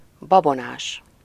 Ääntäminen
IPA : /ˌsupəˈstɪʃəs/